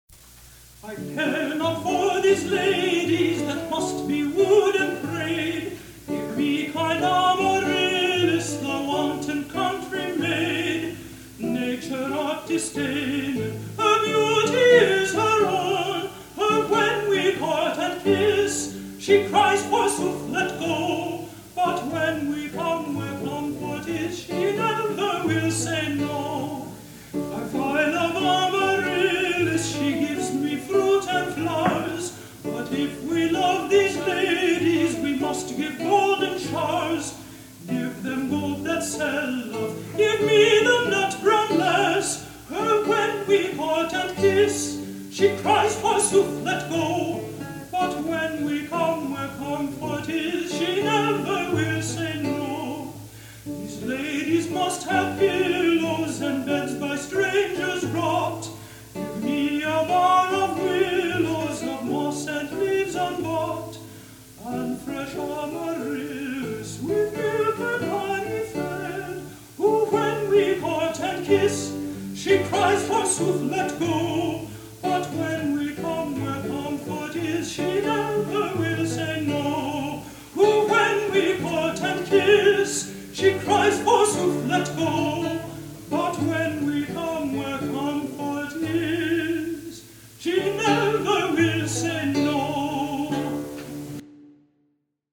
Chamber Consort 'Food of Love' 1982
countertenor
lute